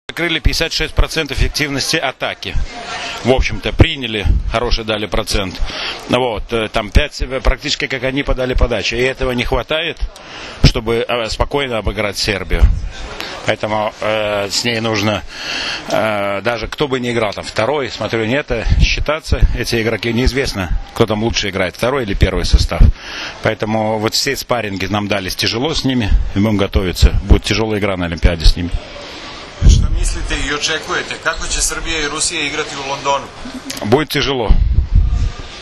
IZJAVA VLADIMIRA ALEKNA